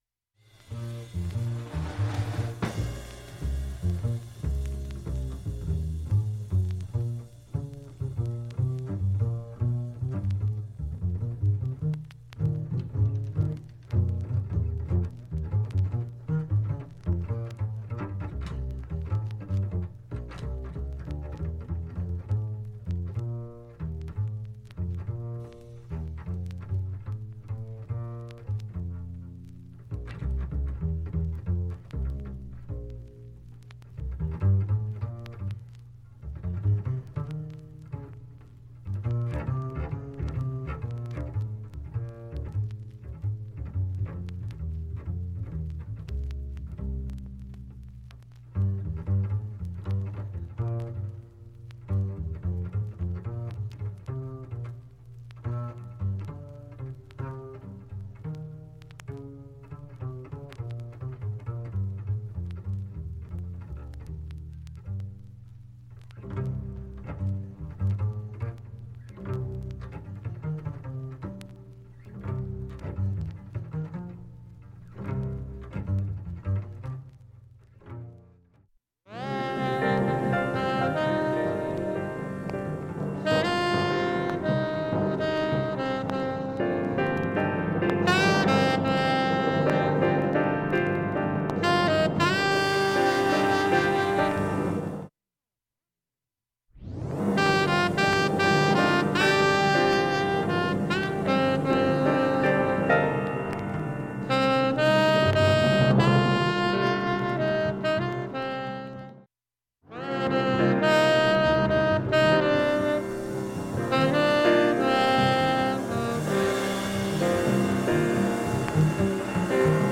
音質良好全曲試聴済み。
B-1中盤にかすかなプツが６回と３回出ます。
６回までのかすかなプツが１箇所
ほか３回までのかすかなプツが２箇所
単発のかすかなプツが２箇所